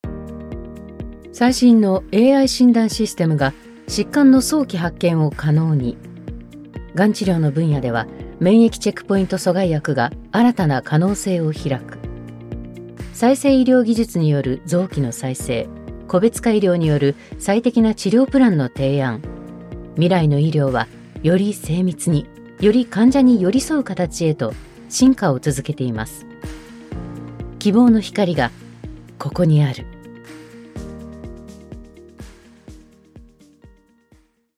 Medizinische Erzählung
Ihre strahlende, tiefe Stimme hat eine ausgeprägte Überzeugungskraft und einen vertrauenerweckenden Klang.